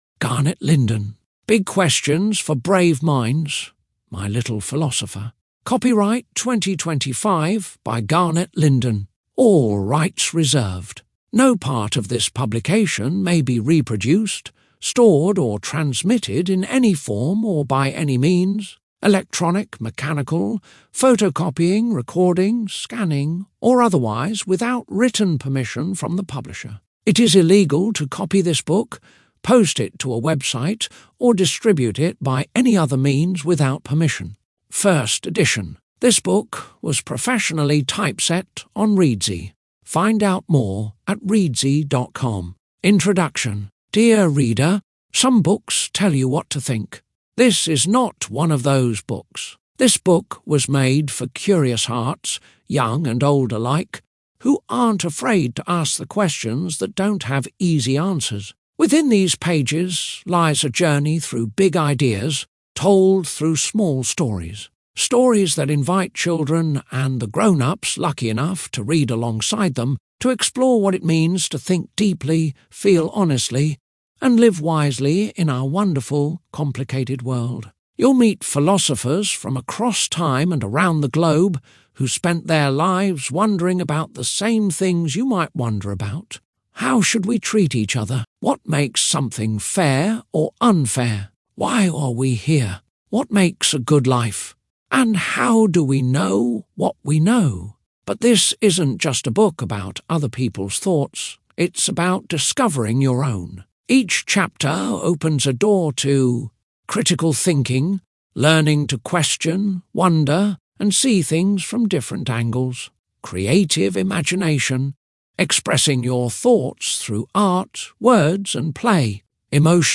big-questions-audiobook.mp3